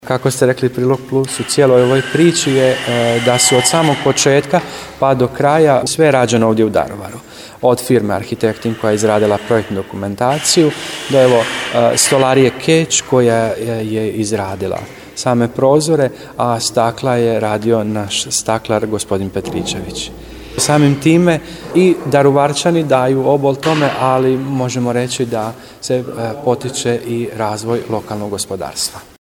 U akustičnom prostoru i bušilica nekako melodično zvuči, tako da je upravo taj radni prostor unutar crkve bio teren s kojeg prenosimo riječi, zvukove, radnu energiju…